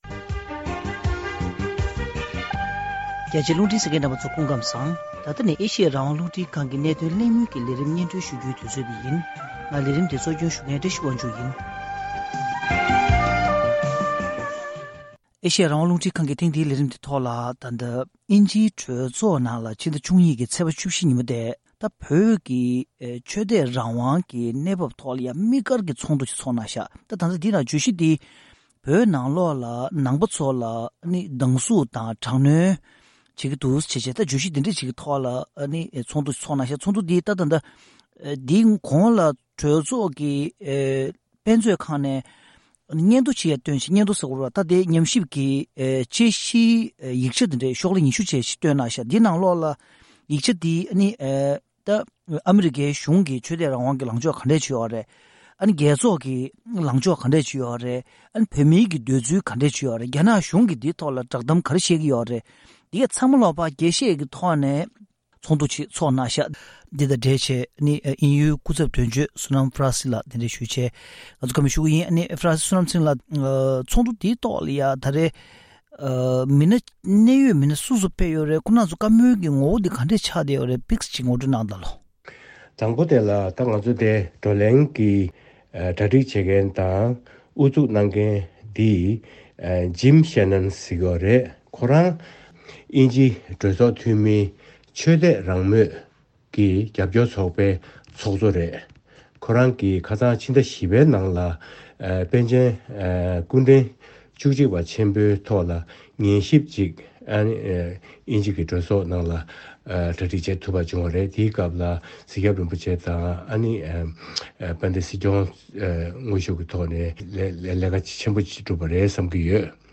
བཀའ་དྲི་ཞུས་པའི་ལས་རིམ་ཞིག་གསན་རོགས་གནང་།